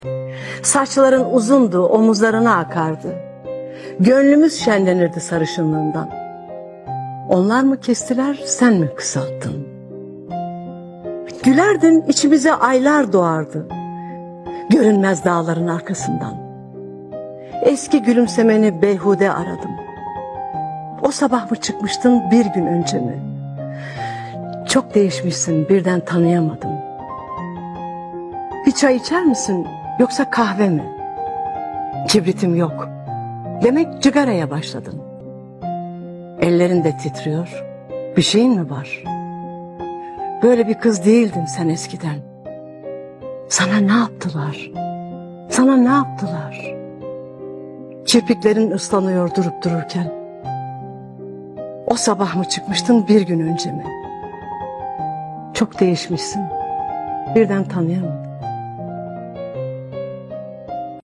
Sesli Şiirler